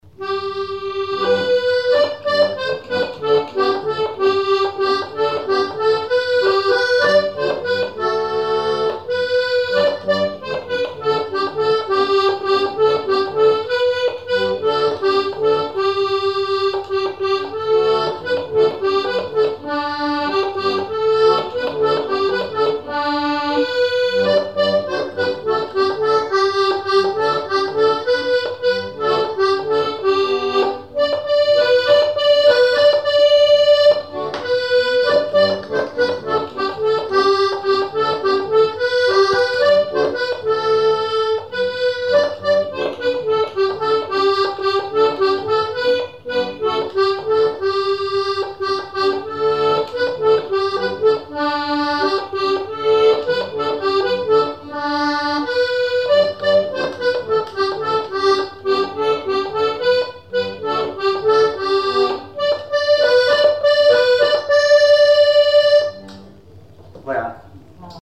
Résumé instrumental
danse : valse
Genre strophique
Pièce musicale inédite